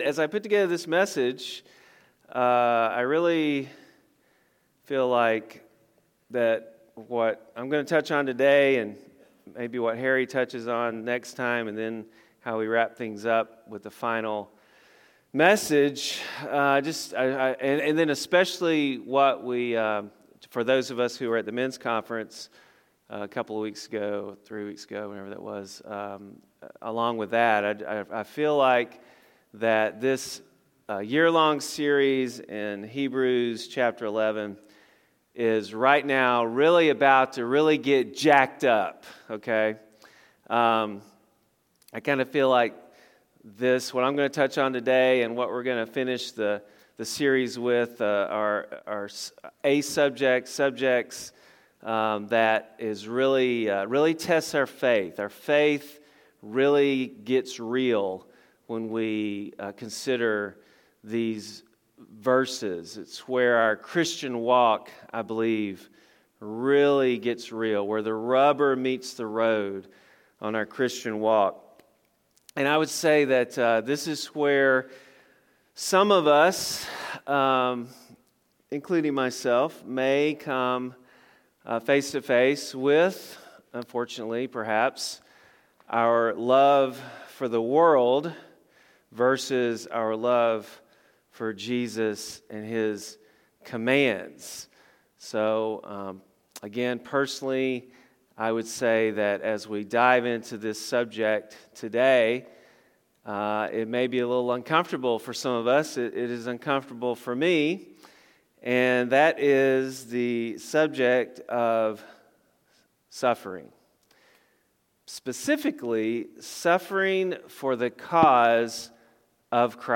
A message from the series "Ironworks."